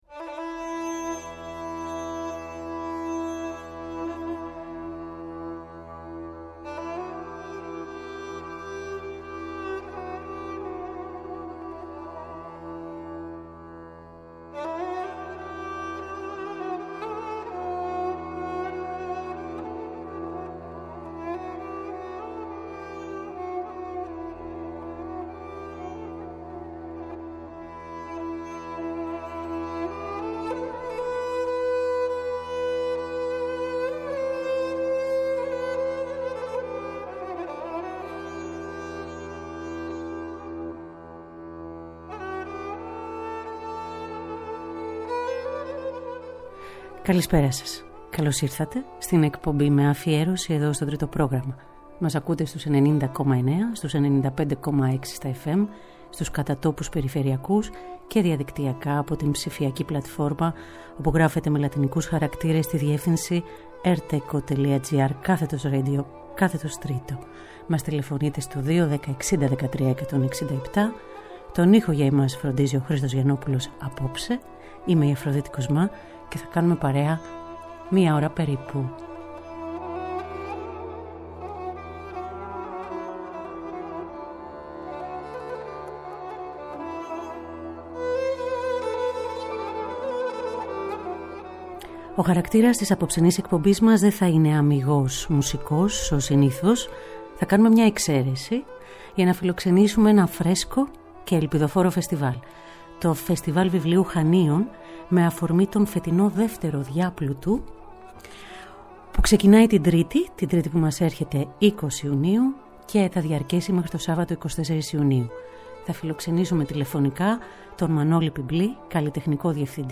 Ζωντανά από το στούντιο του Τρίτου Προγράμματος.